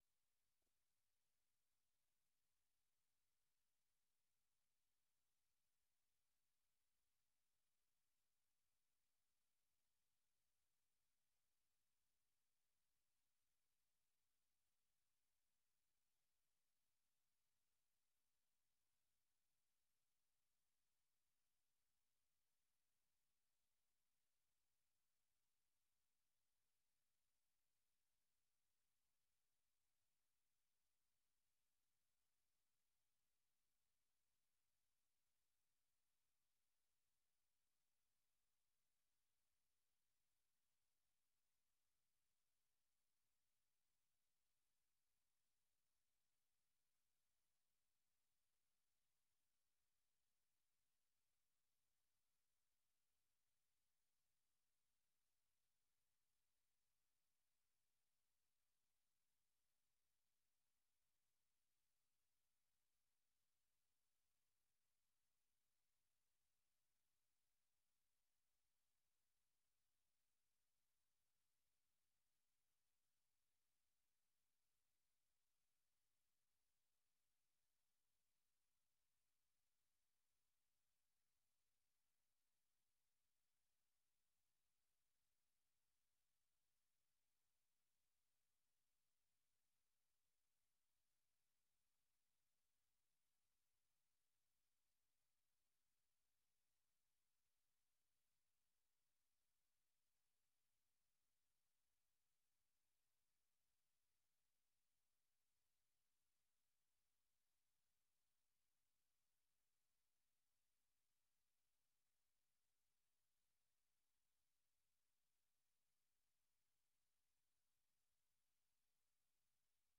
El punto de encuentro para analizar y debatir, junto a expertos, los temas de la semana.